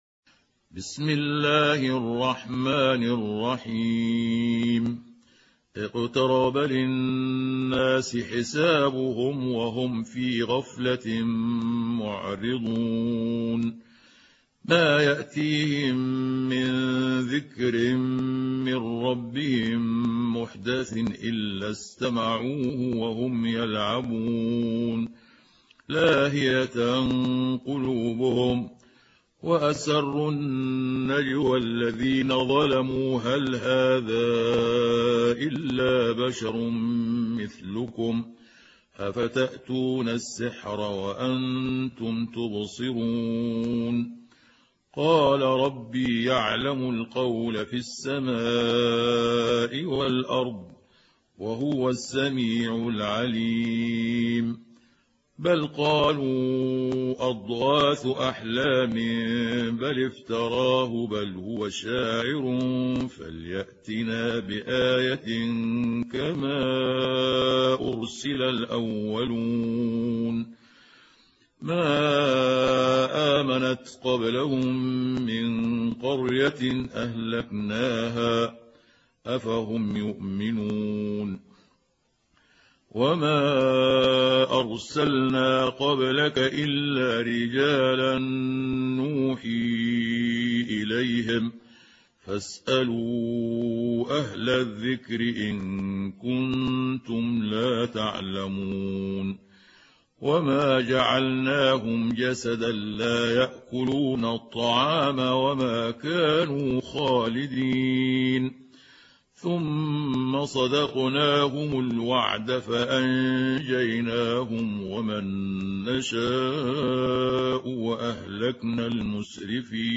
سورة الأنبياء | القارئ محمود عبد الحكم